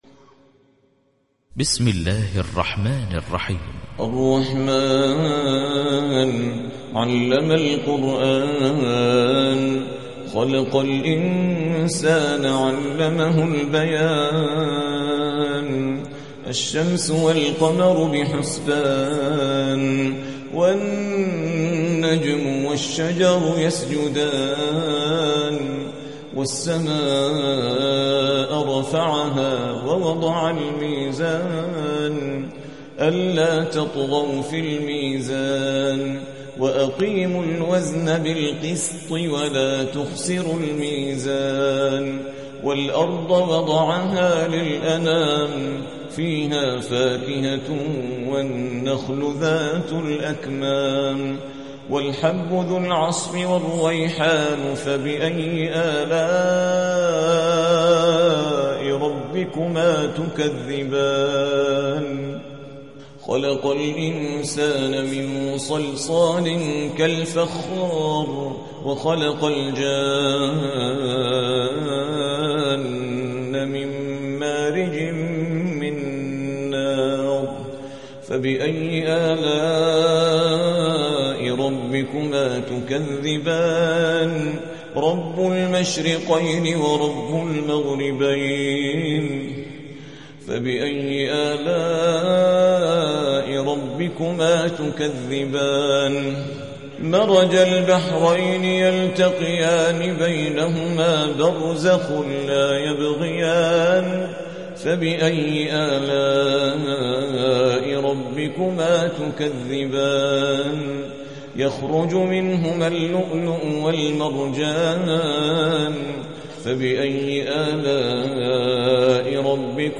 55. سورة الرحمن / القارئ